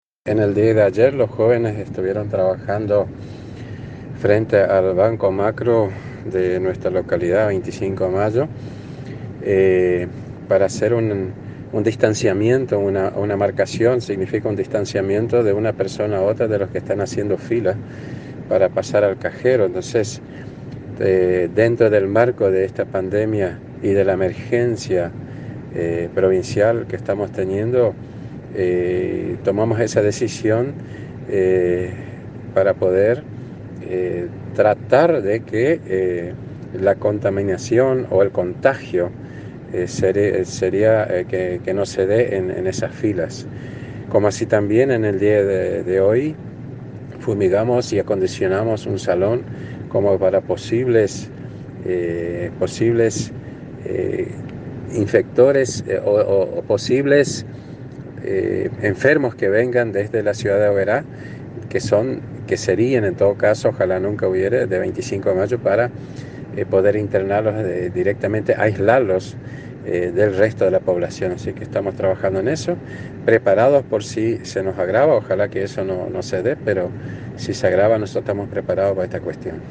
En diálogo con la Agencia de Noticias Guacurarí el mandatario brindó detalles de los trabajos.